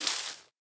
Minecraft Version Minecraft Version latest Latest Release | Latest Snapshot latest / assets / minecraft / sounds / block / cherry_leaves / step4.ogg Compare With Compare With Latest Release | Latest Snapshot
step4.ogg